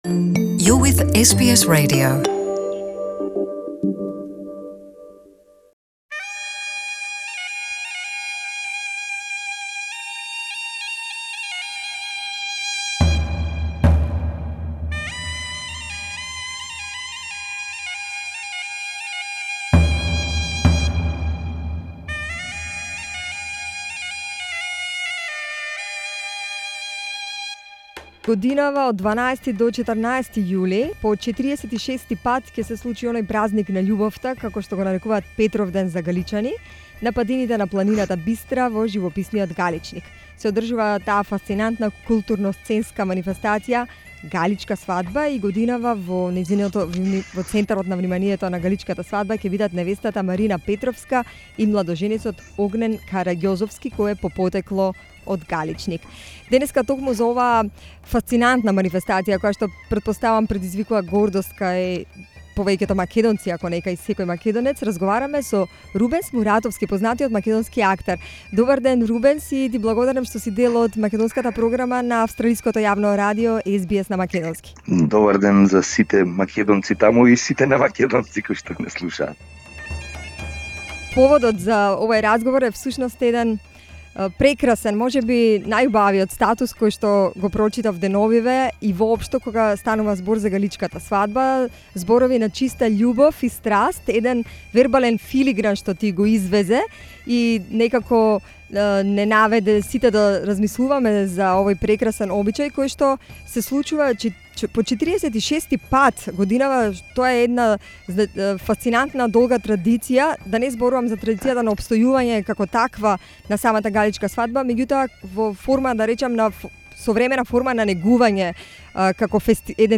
SBS Macedonian discusses the details of this Macedonian cultural event